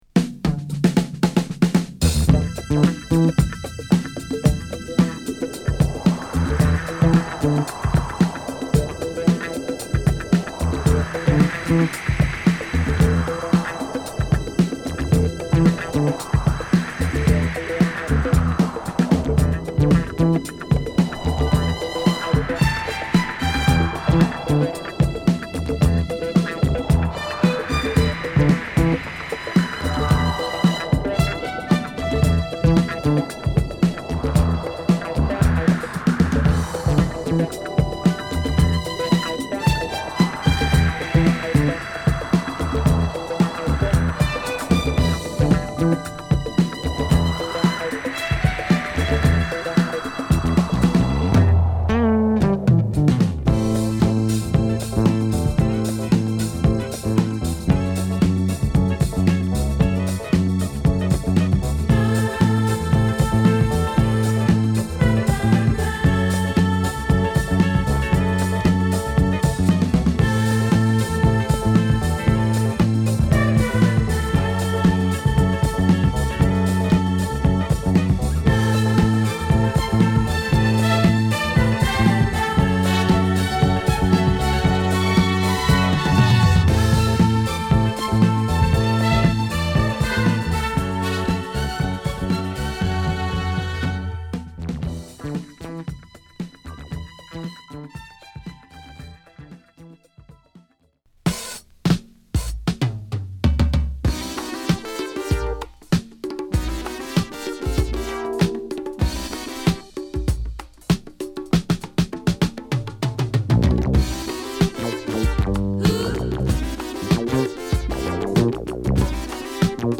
良質ジャーマン・ディスコ！